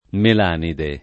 [ mel # nide ]